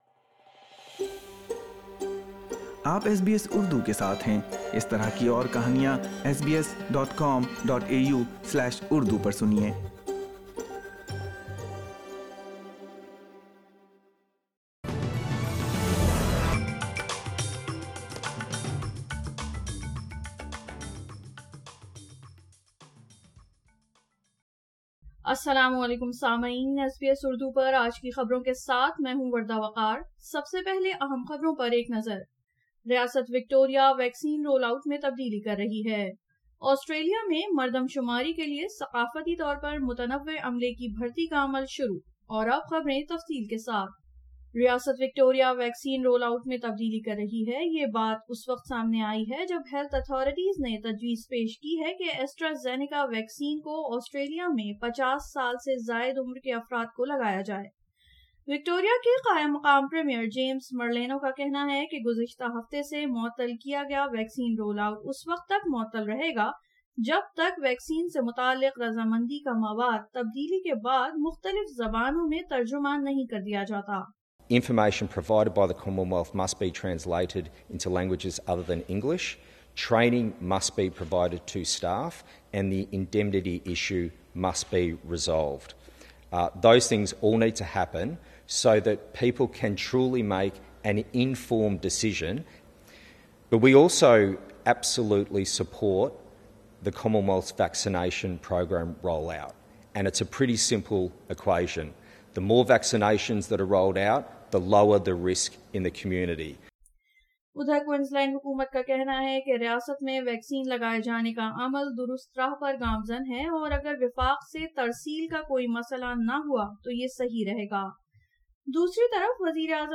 اردو خبریں 12 اپریل 2021